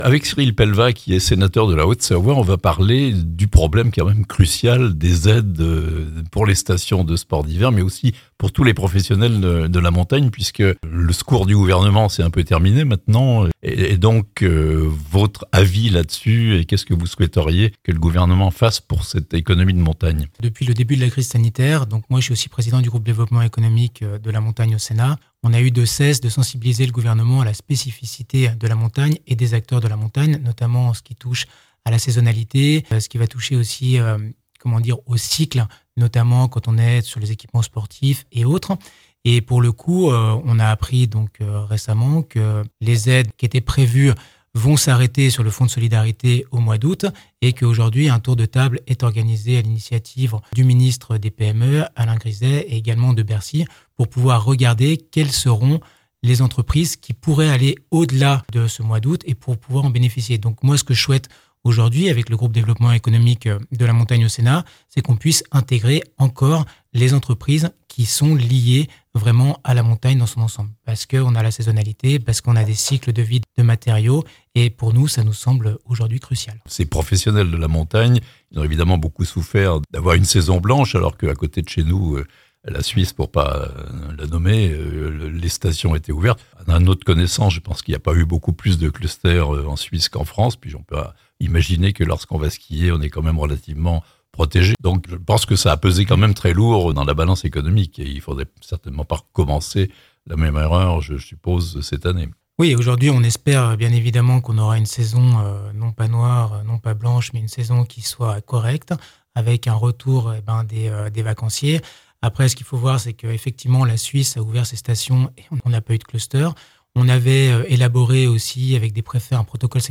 Fin des aides gouvernementales pour les stations de montagne ? (interview)
itw-cyril-pellevat-aides-aux-stations-de-montagne-6748.mp3